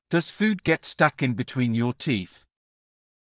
ﾀﾞｽﾞ ﾌｰﾄﾞ ｹﾞｯ ｽﾀｯｸ ｲﾝ ﾋﾞﾄｩｳｨｰﾝ ﾕｱ ﾃｨｰｽ